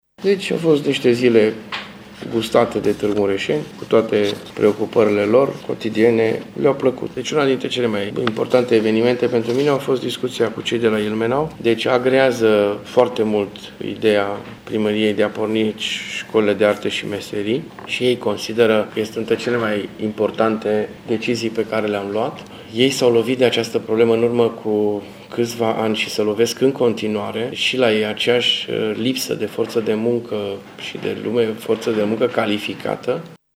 Primarul Dorin Florea a subliniat importanţa discuţiilor avute cu delegaţiile oraşelor înfrăţite sosite la Tg.Mureș cu acest prilej: